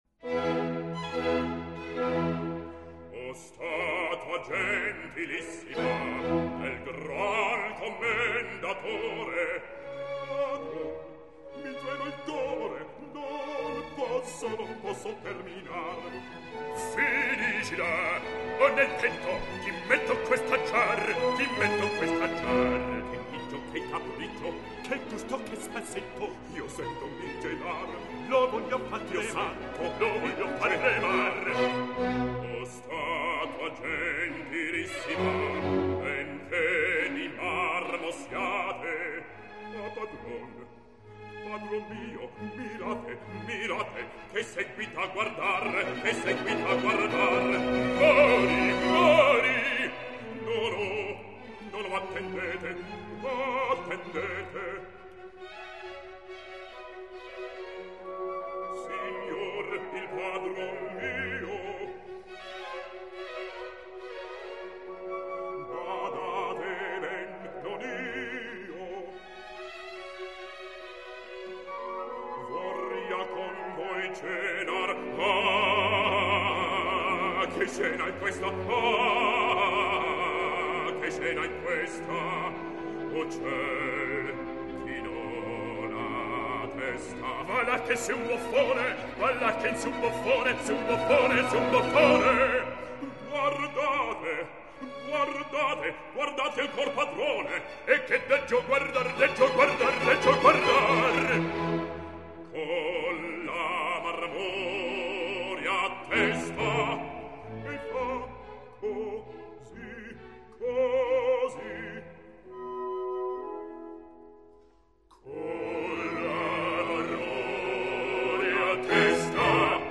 05 - Duetto - O Statua Gentilissima.mp3 — Laurea Triennale in Scienze e tecnologie della comunicazione